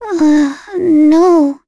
Lavril-Vox-Deny1.wav